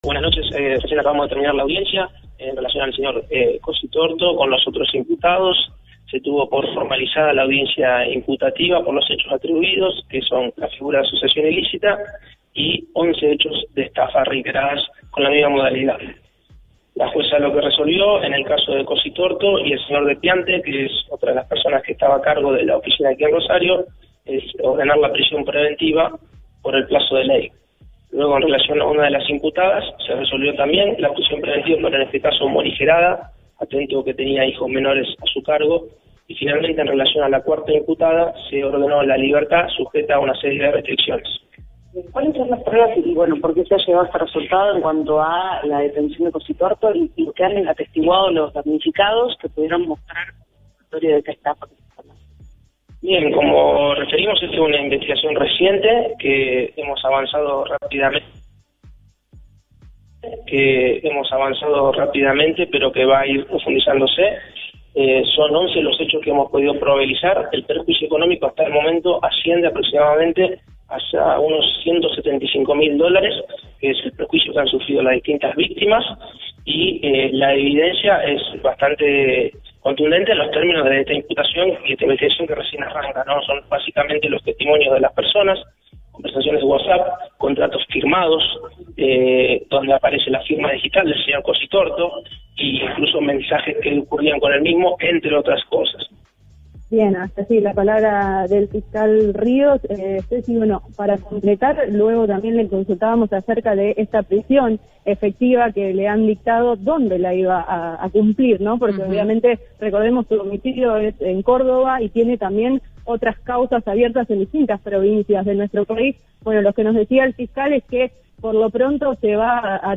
La palabra del fiscal Mariano Ríos tras la audiencia imputativa de Leonardo Cositorto